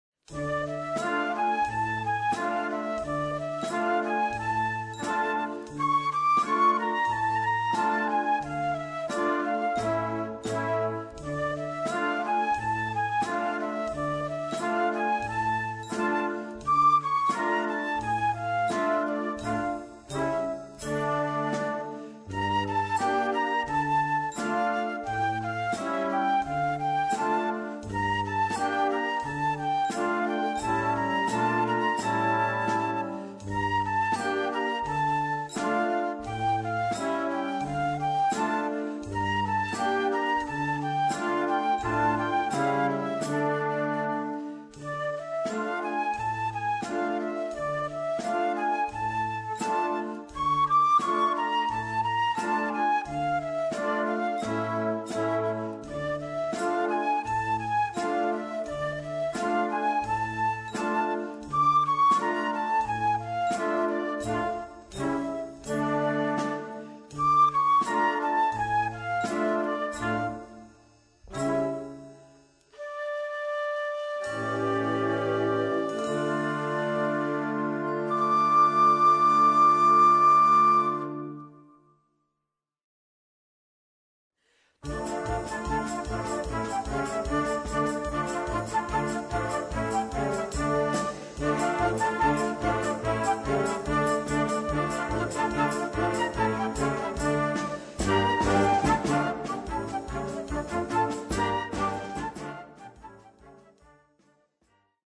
Groupe de flûtes à bec avec accompagnement orchestral
Partitions pour ensemble flexible, 4-voix + percussion.